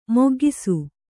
♪ moggisu